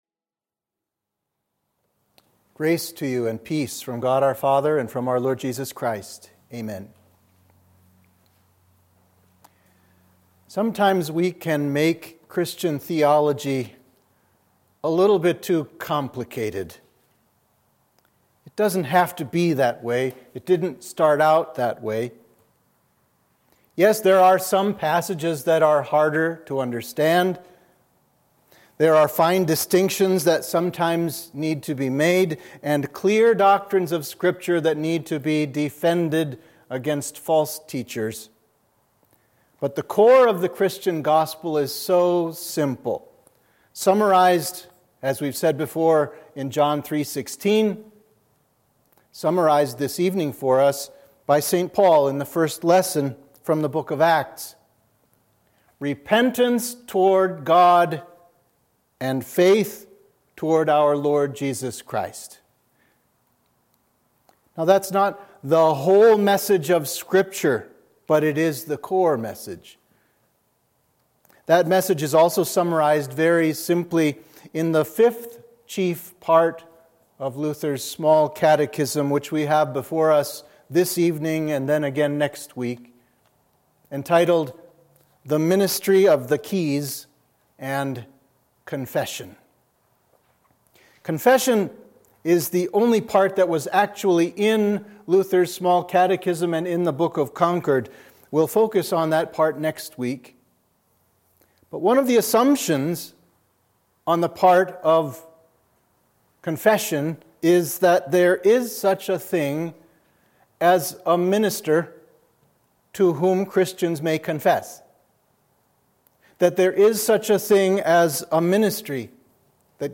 Sermon for the Festival of St. Titus